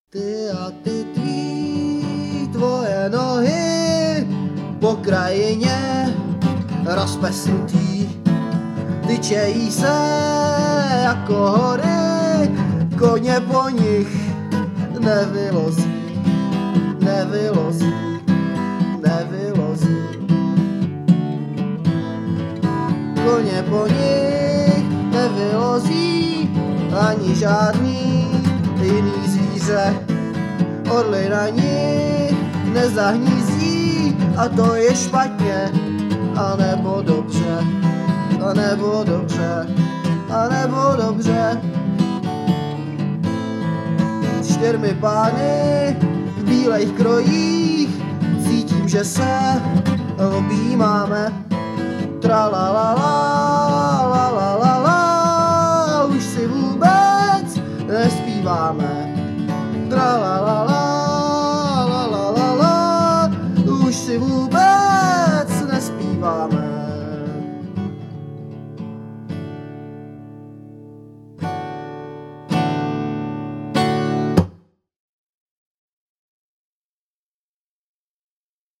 kbd, harm, dr
g, sitar, harm